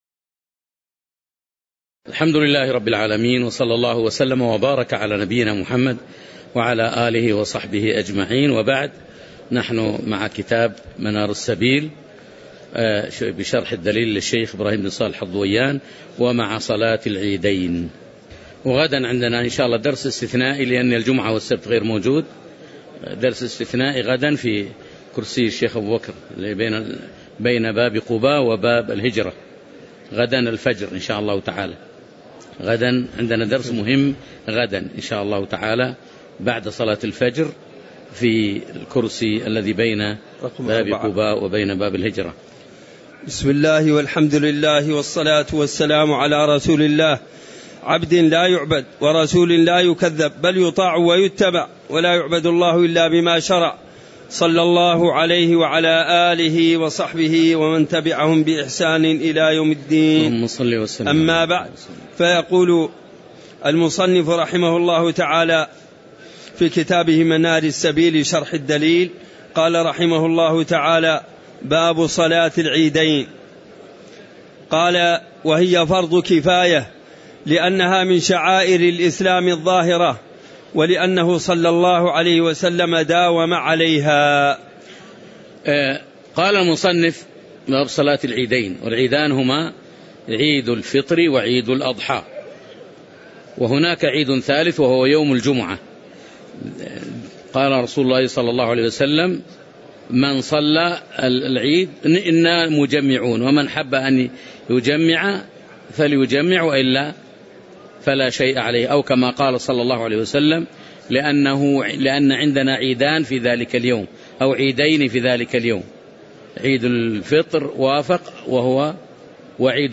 تاريخ النشر ١١ ربيع الأول ١٤٣٩ هـ المكان: المسجد النبوي الشيخ